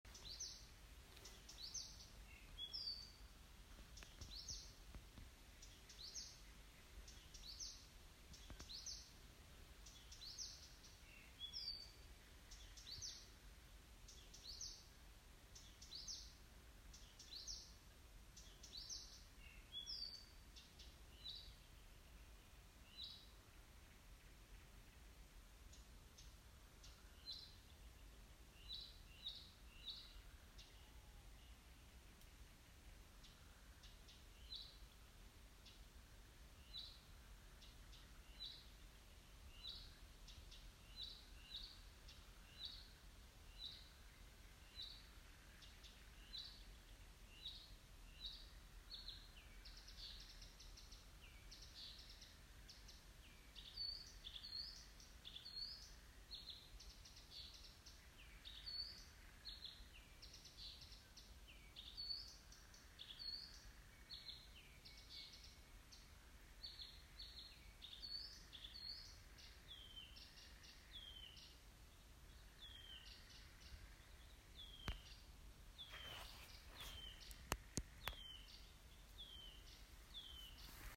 садовая камышевка, Acrocephalus dumetorum
Administratīvā teritorijaPriekuļu novads
СтатусПоёт